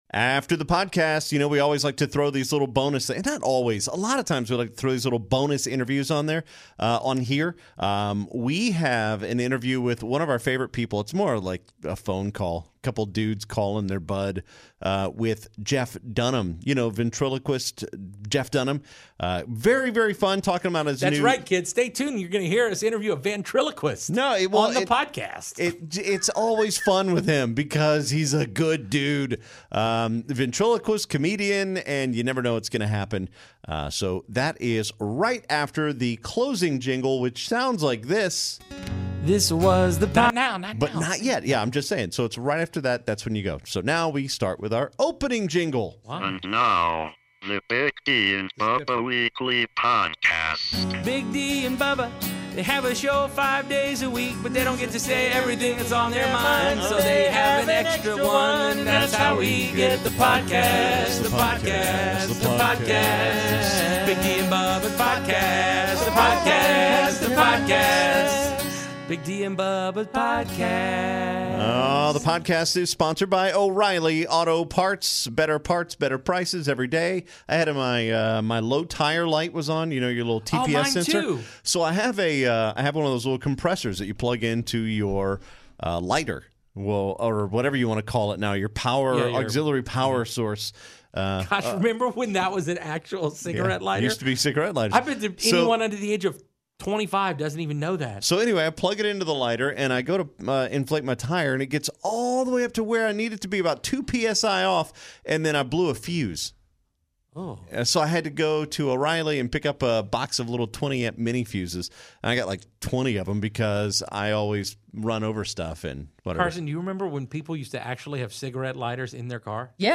Comedian Jeff Dunham joins us at the end of Weekly Podcast #280